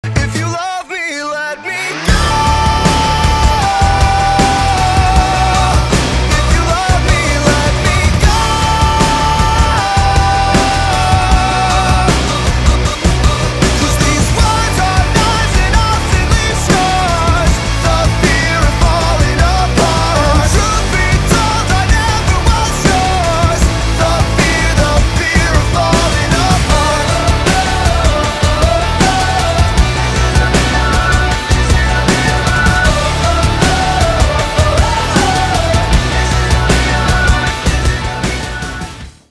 • Качество: 320, Stereo
мужской вокал
громкие
мелодичные
красивая мелодия
Alternative Rock
indie rock
мелодичный рок